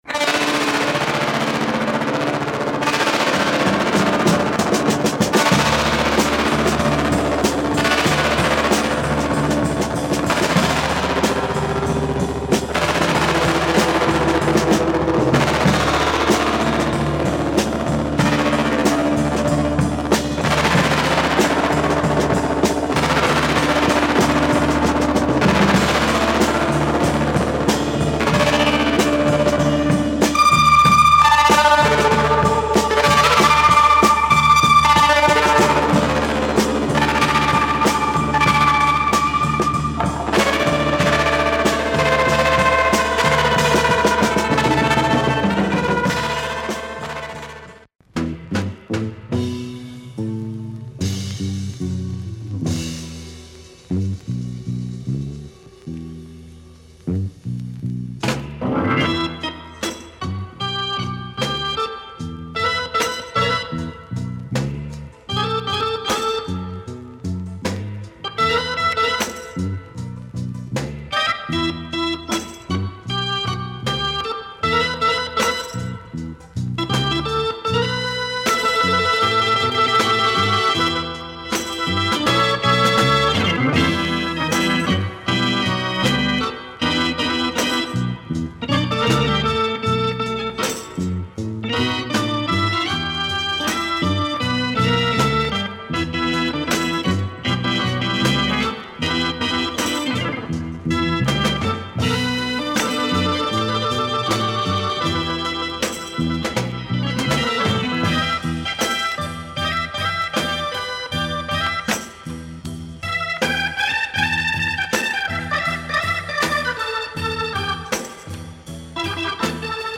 Monster psychedelic groove with lots of fuzz guitar
Copious breaks !